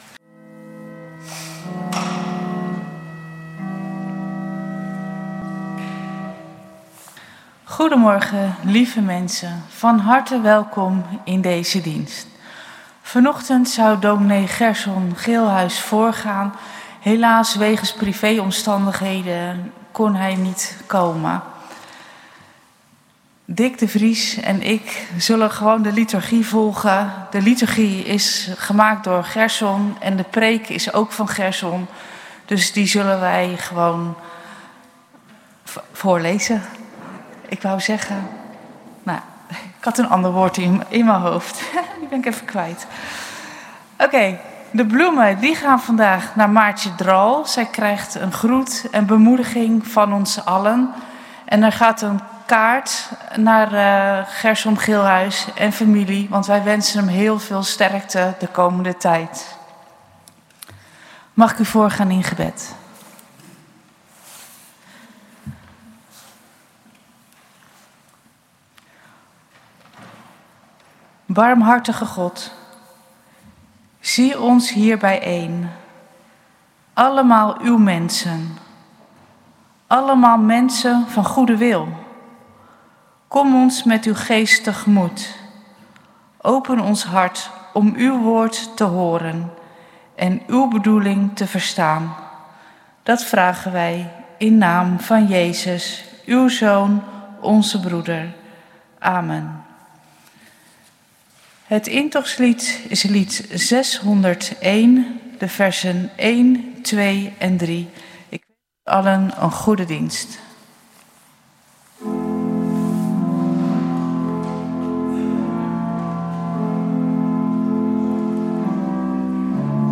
Kerkdienst geluidsopname